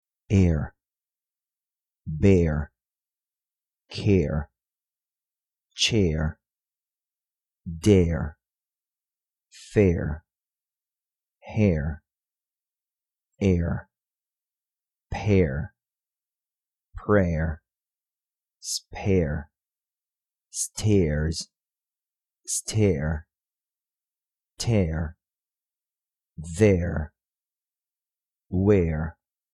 Temos a tendência de dizer “quér” e “dér” com um som mais curto, porém o som em questão é um pouco mais longo e articulado.
pronunciation-02-care.mp3